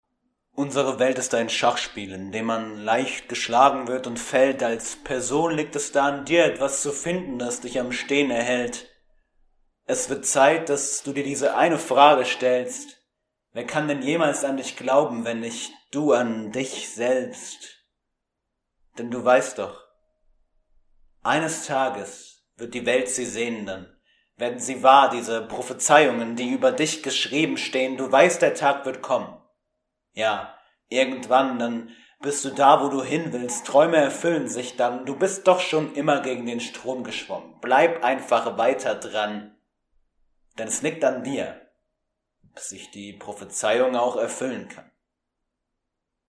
Auszug eines Poetry Slams